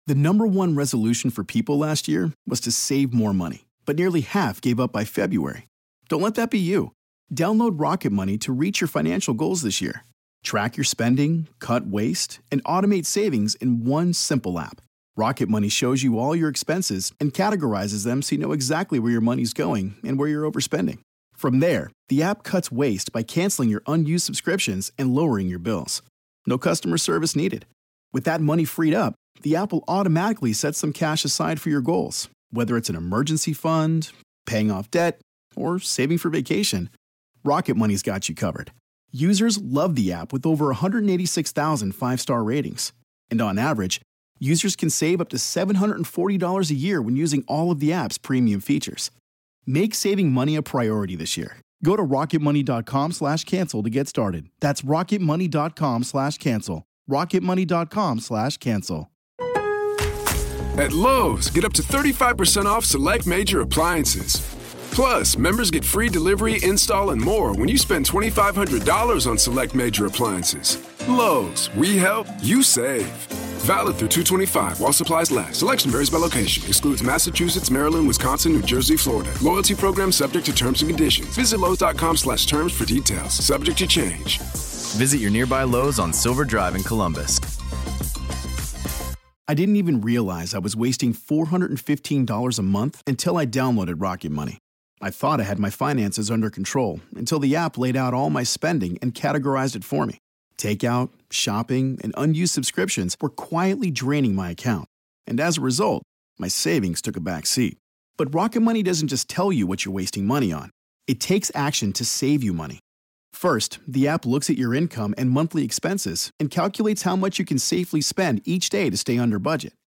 How does one jump into the world of paranormal investigation? What do you do when you accidentally leave an investigation with more souls than you walked in with? Today we have an in-depth conversation